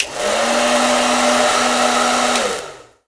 Blow Dryer 05
Blow Dryer 05.wav